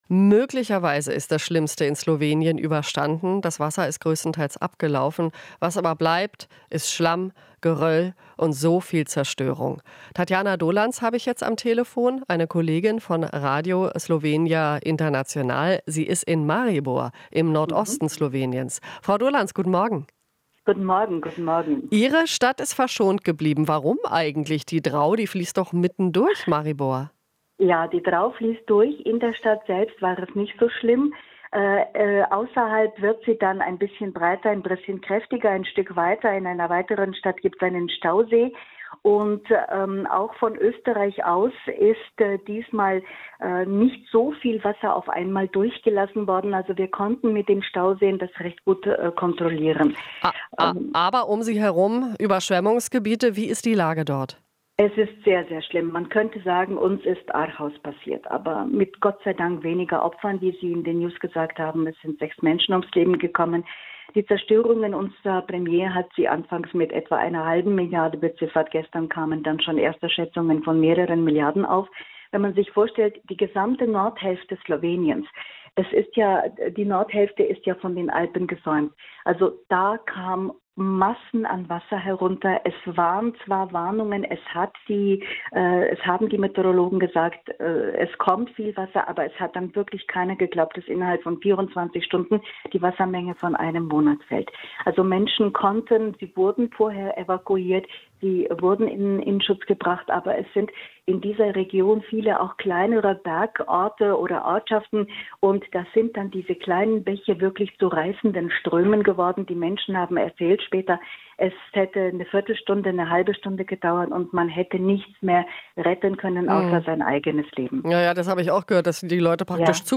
Interview - Slowenische Journalistin berichtet: "Viele sind ohne alles geblieben"